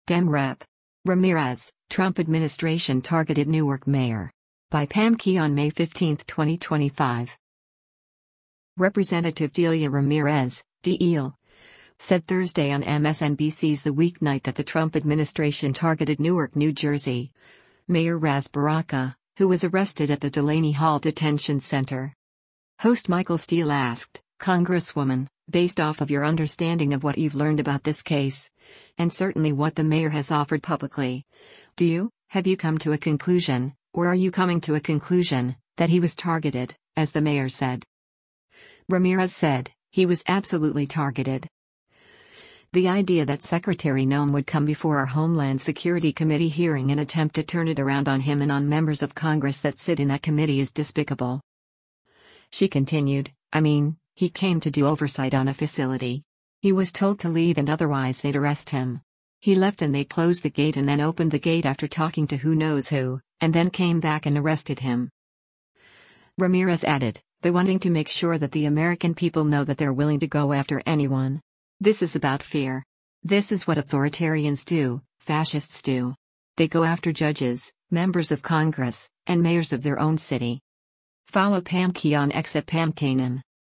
Representative Delia Ramirez (D-IL) said Thursday on MSNBC’s “The Weeknight” that the Trump administration targeted Newark, NJ, Mayor Ras Baraka, who was arrested at the Delaney Hall Detention Center.
Host Michael Steele asked, “Congresswoman, based off of your understanding of what you’ve learned about this case, and certainly what the mayor has offered publicly, do you — have you come to a conclusion, or are you coming to a conclusion, that he was targeted, as the mayor said?”